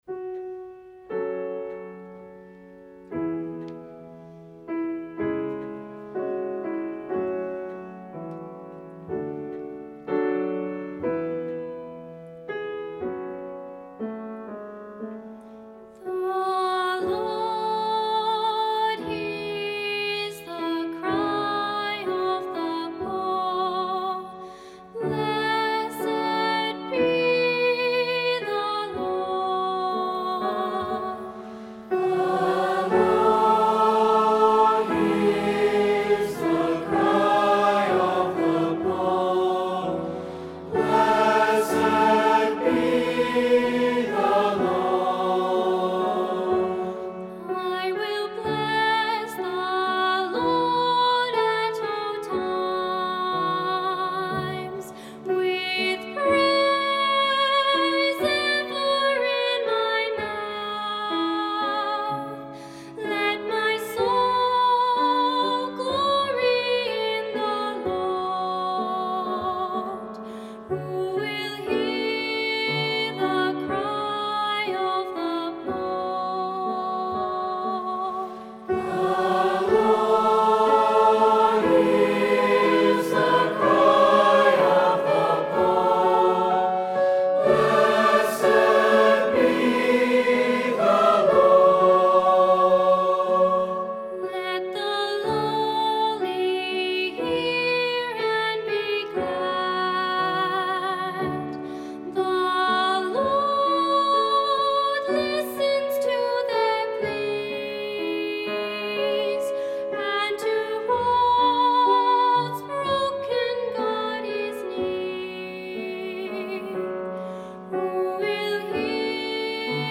The Lavalla Liturgical Choir provided appropriate hymns and Taizé chants.
Such a moment occurred when the Liturgical choir sang at this public liturgy of Re-Membering
And in the silence that followed… a silence heavy with loss and doubt, a single voice, with the clarity of a silver bell dropped like the dewfall into our midst: The Lord hears the cry of the Poor.
The choir was to the side of the auditorium… not a performance… like a hidden presence confirming the words of Paul… as if heaven had answered with the voice of an angel.